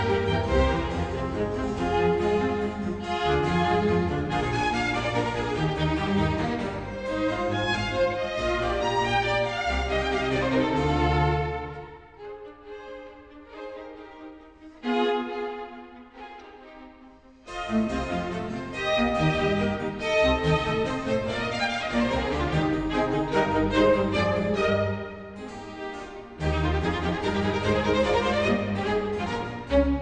classical.00005.wav